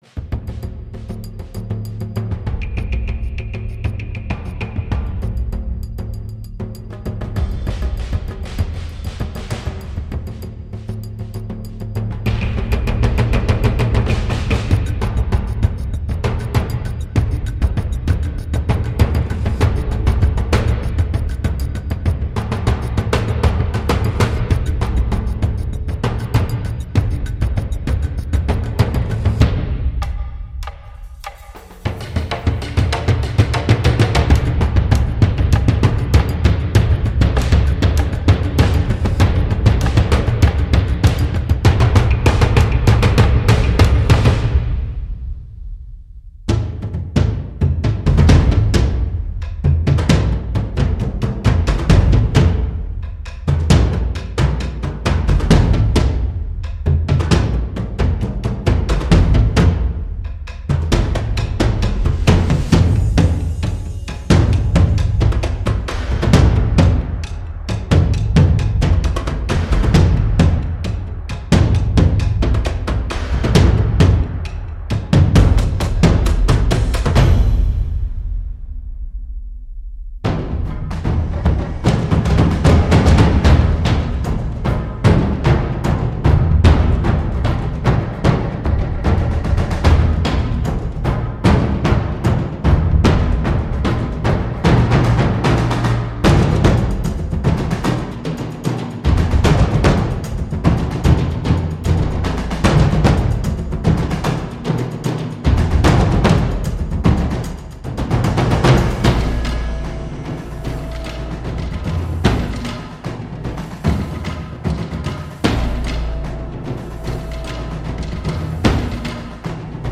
它包含了 40 种不同的鼓类乐器，如低音鼓、军鼓、苏尔多鼓、转鼓、中音鼓、框鼓和小鼓等，以及各种边缘敲击、木质和金属打击乐器，还有大量的声音设计元素，如大爆炸、氛围、金属、机械效果、摩擦皮肤和铜钹等。
这款乐器适合用于电影、游戏、电视等媒体的配乐，特别是需要强烈的气氛和张力的场景。
• 它提供了大量的 MIDI 表演文件，让你可以快速地生成节奏感强烈的打击乐轨道。